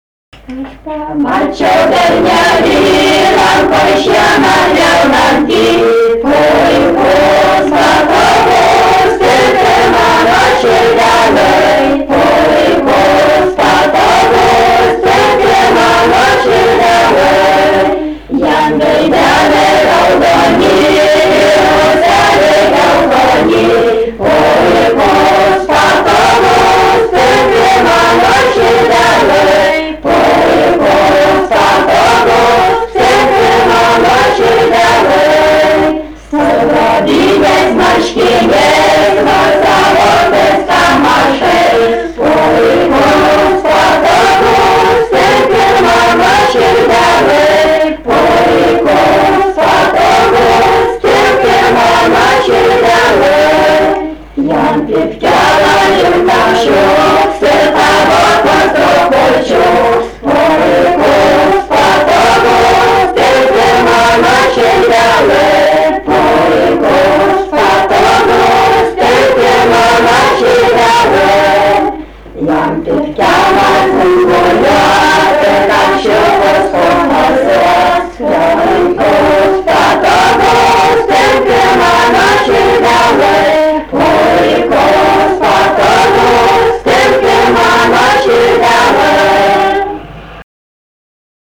Moterų grupė
daina
Medeikiai
vokalinis